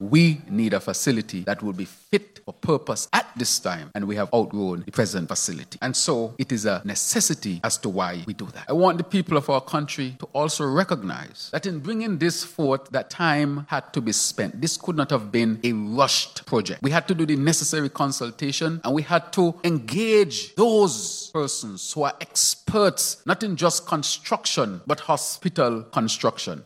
A ceremony was held at the St. Kitts Marriott Resort on Friday, February 7th to unveil the designs in 3D version of the new “Climate-Smart Joseph N. France General Hospital”.
The Hon. Dr. Terrance Drew, Prime Minister and Minister of Health gave these remarks: